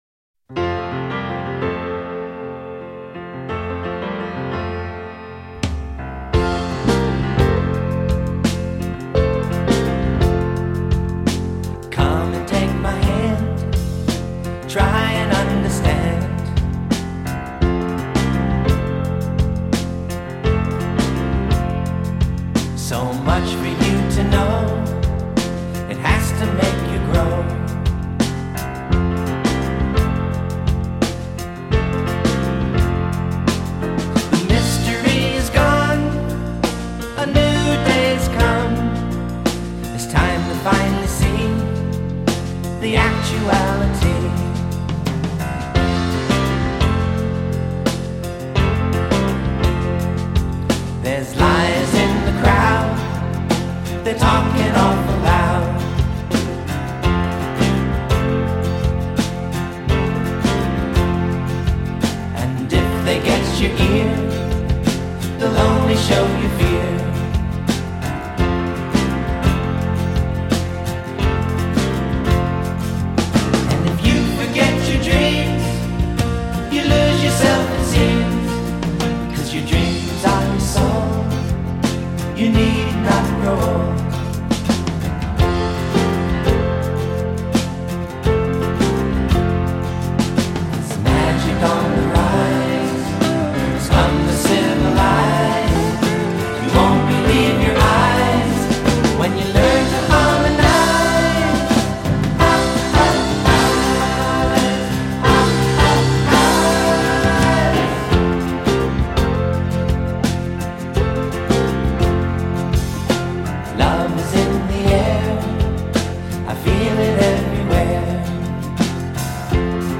Listen to the Title Song